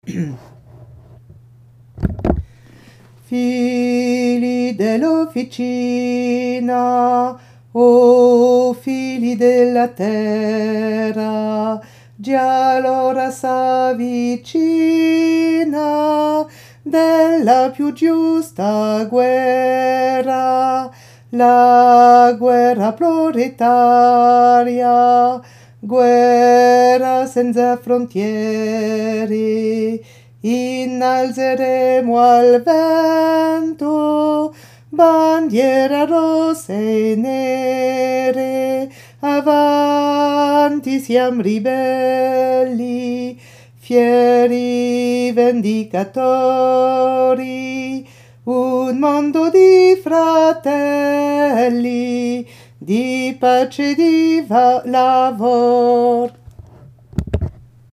Figli alti
figli-alti.mp3